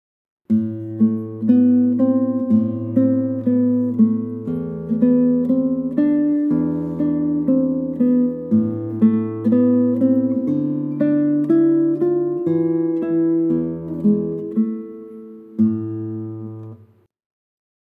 The resulting sound is smoother and more flowing than simply releasing the note.
Let Ring | The let ring symbol indicates a sustain of the note so it overlaps the next note without delaying or changing the beat.